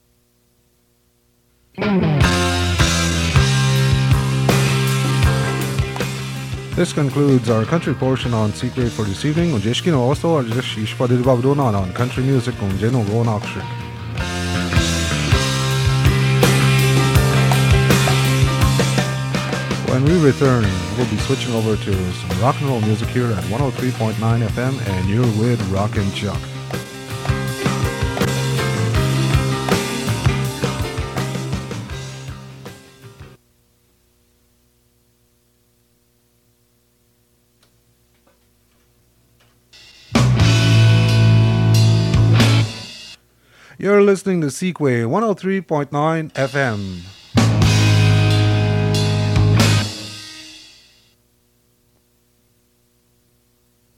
Radio jingles